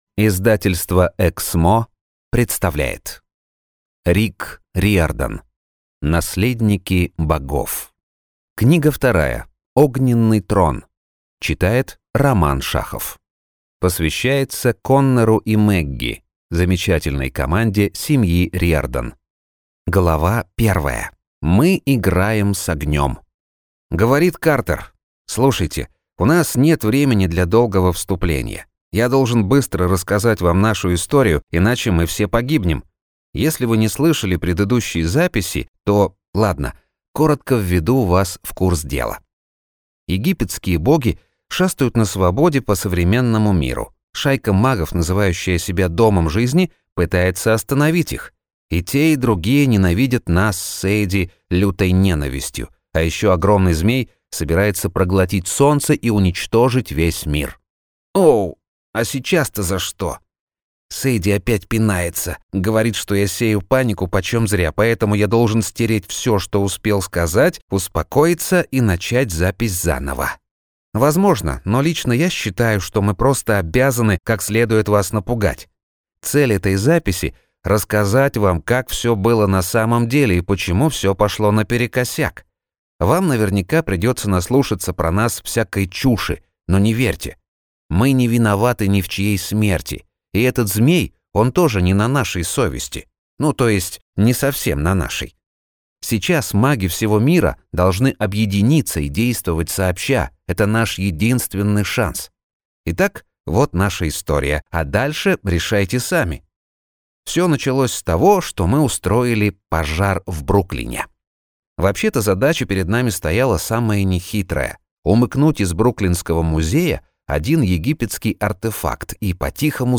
Аудиокнига Огненный трон | Библиотека аудиокниг